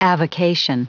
Prononciation du mot avocation en anglais (fichier audio)
Prononciation du mot : avocation